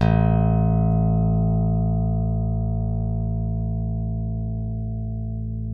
ALEM PICK A1.wav